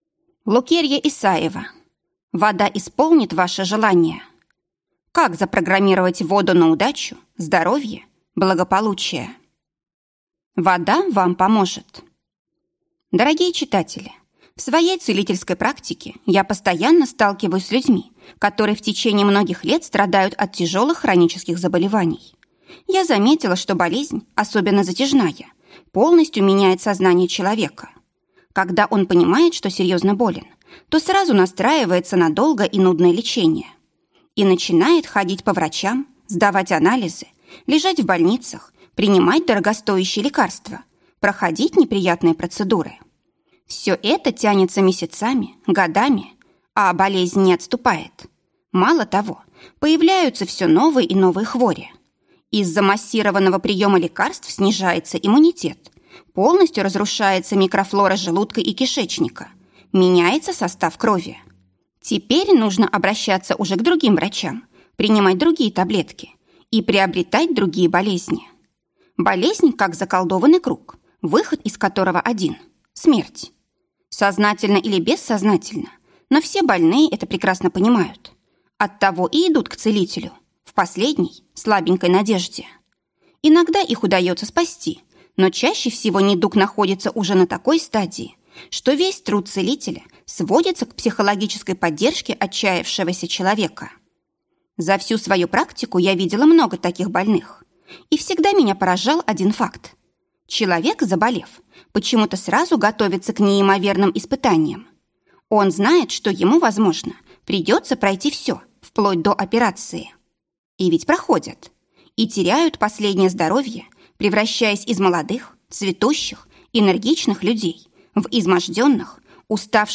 Аудиокнига Вода исполнит ваши желания. Как запрограммировать воду на удачу, здоровье, благополучие | Библиотека аудиокниг